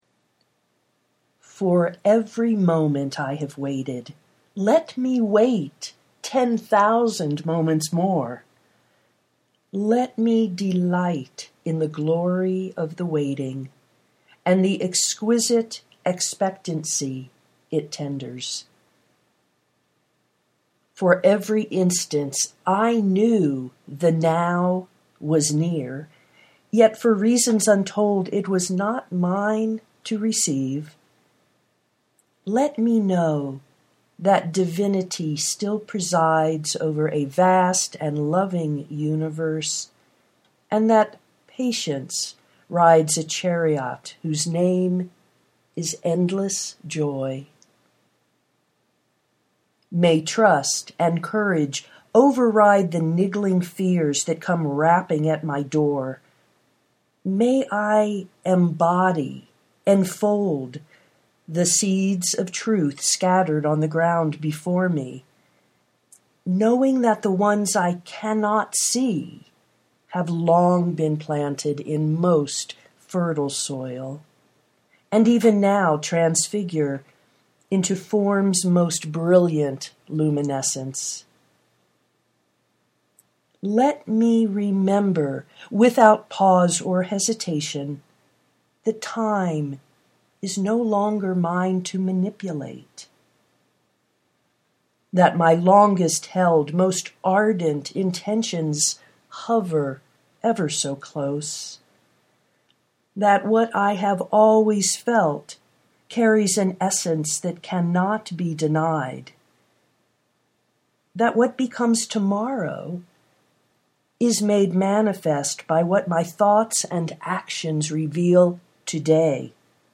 prayer for radical patience (audio poetry 4:24)